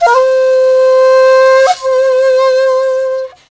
BRA SHAKU.wav